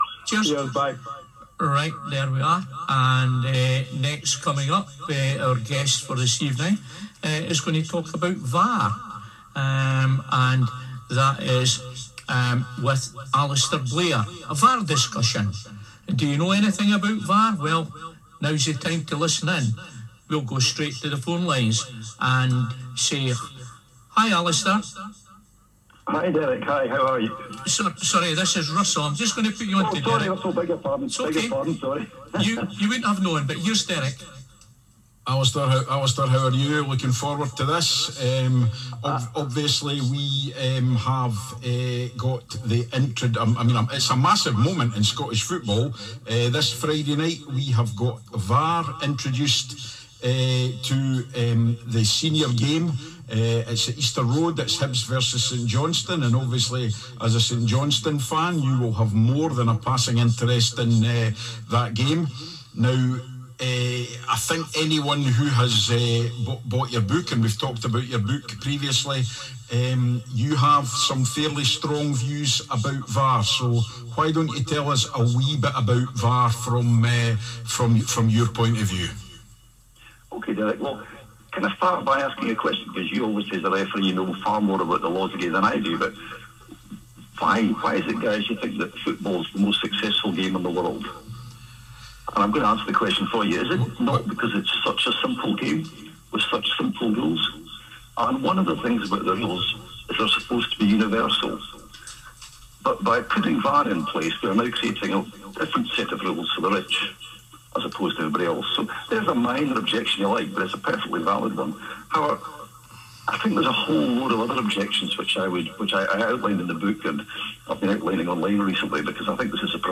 VAR interview - live on Black Diamond FM